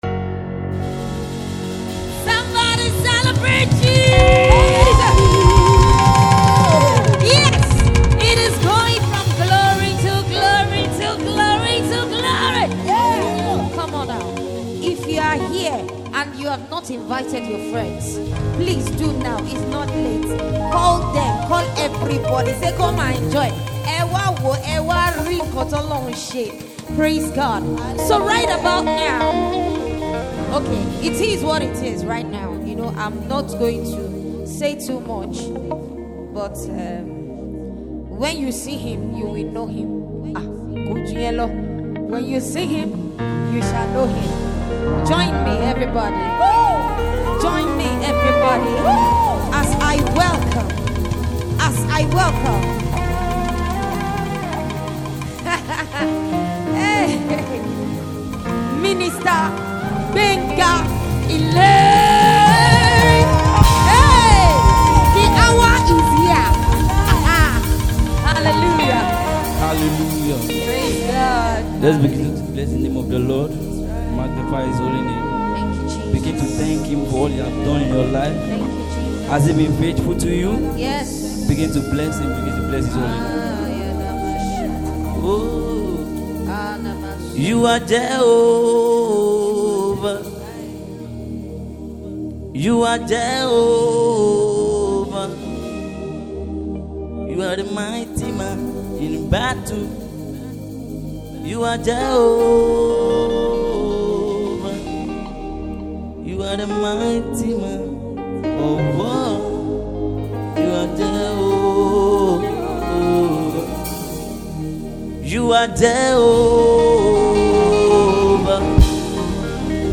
gospel
high-energy praise song
powerful vocals